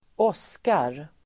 Uttal: [²'ås:kar]